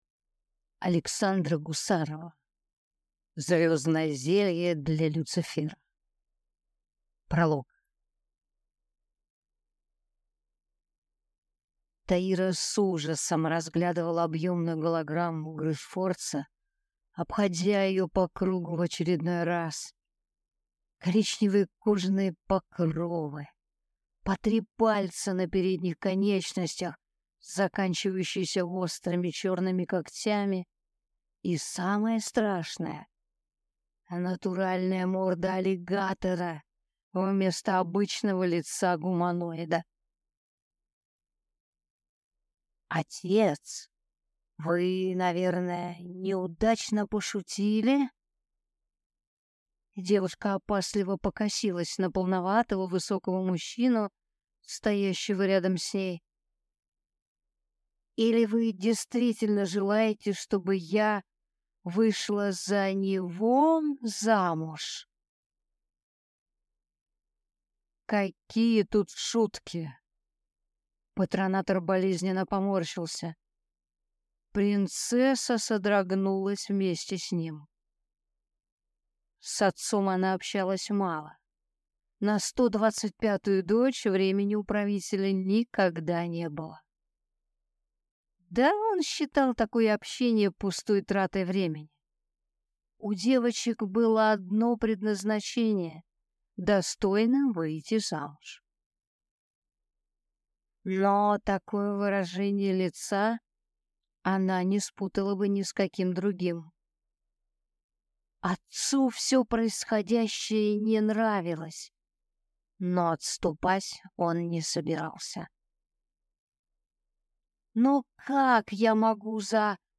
Аудиокнига Звёздное зелье для Люцифера | Библиотека аудиокниг
Прослушать и бесплатно скачать фрагмент аудиокниги